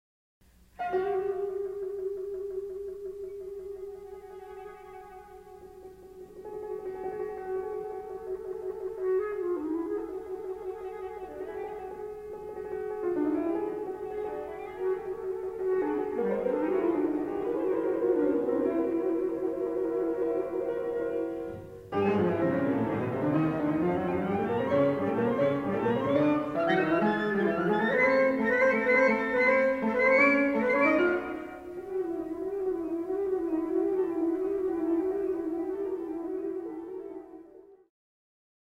flute, bass clarinet, piano